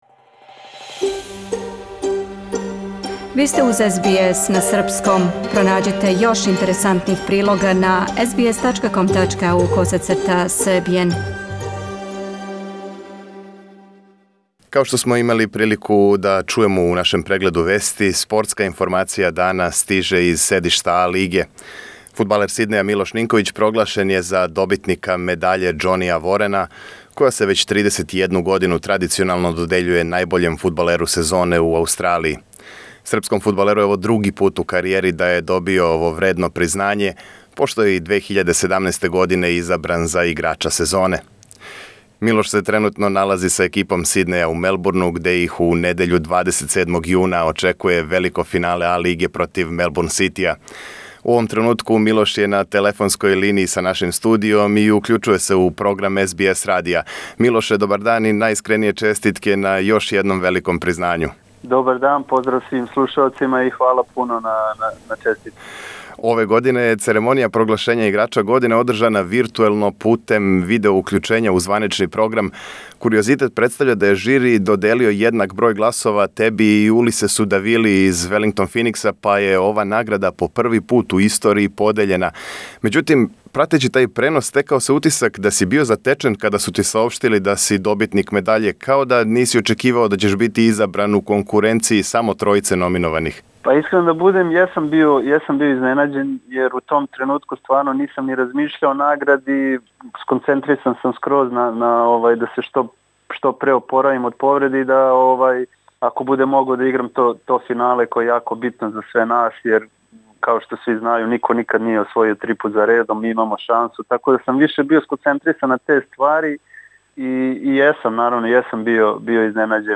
Фудбалер Сиднеја Милош Нинковић по други пут је изабран за најбољег играча А-лиге. У ексклузивном интервјуу за СБС радио, српски ас говори о значају престижног признања, о повреди, о хитној "евакуацији" из Сиднеја, као и о предстојећем великом финалу против Мелбурн Ситија, у којем ће он и његови саиграчи покушати да ураде нешто што до сада никоме у Аустралији није пошло за руком.